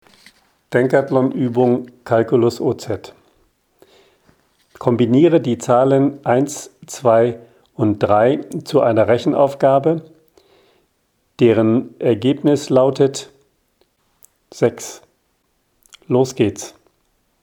Die ungemischten Audio-Pakete enthalten insgesamt mehr als 4.500 gesprochene Aufgaben.